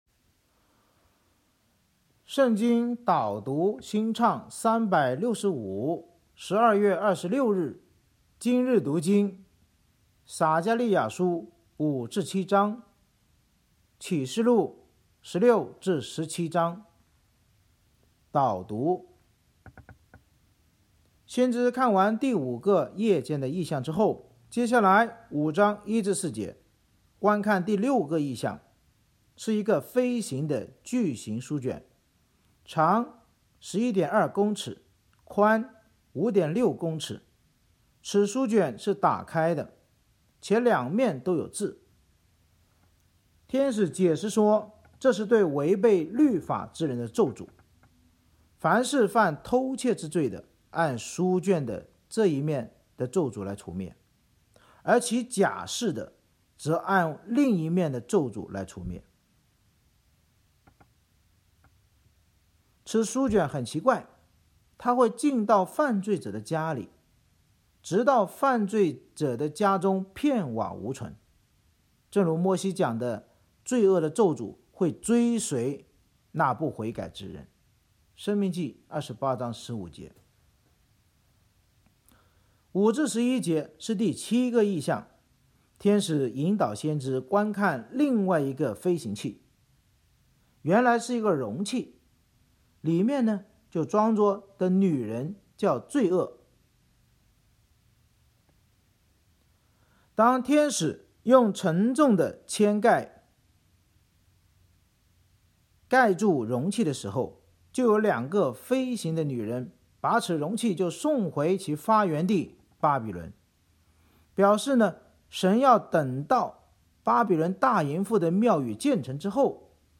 圣经导读&经文朗读 – 12月26日（音频+文字+新歌）